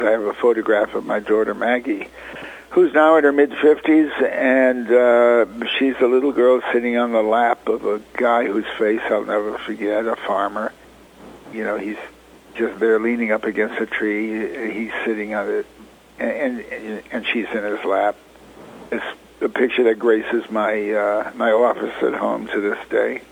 Lear returned to Iowa in 2014, and in a Radio Iowa interview, recalled how “Cold Turkey” came out the same year his show, “All in the Family” premiered on C-B-S. The ground-breaking sitcom dealt with controversial issues previously ignored in comedies.